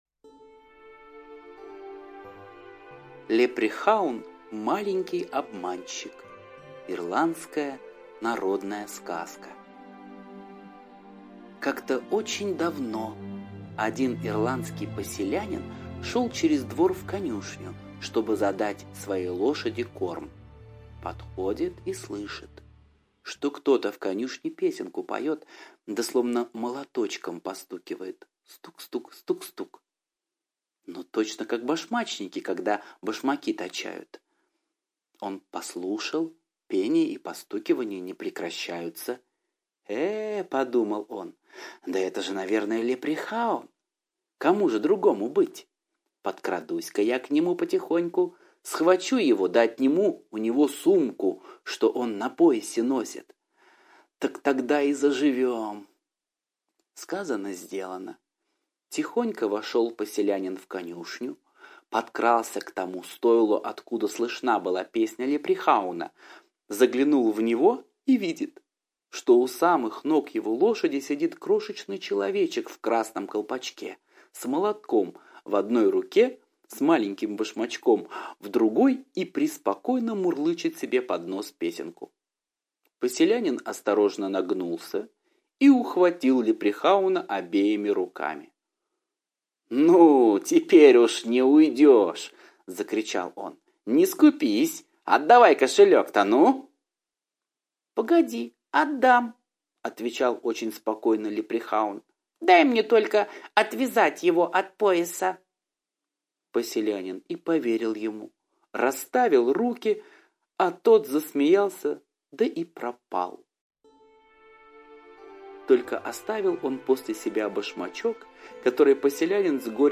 Лепрехаун-маленький обманщик - ирландская аудиосказка - слушать онлайн